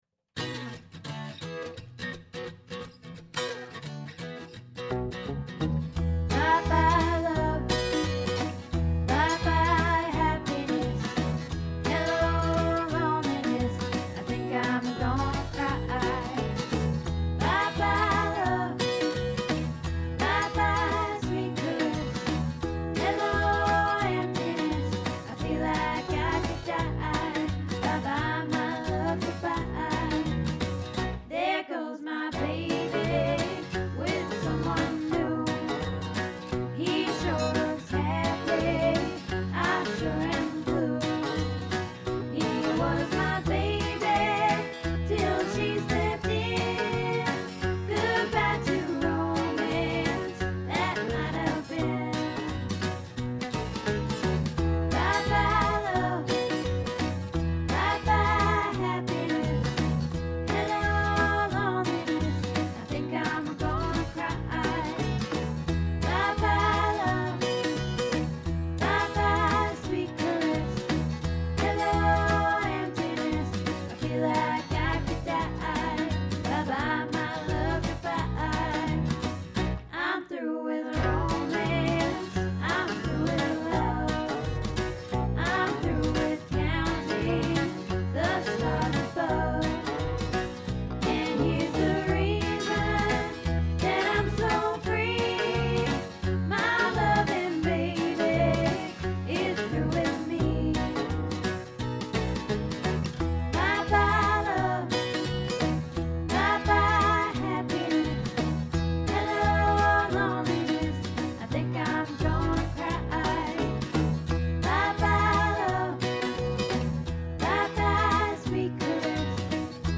Bye Bye Love Tenor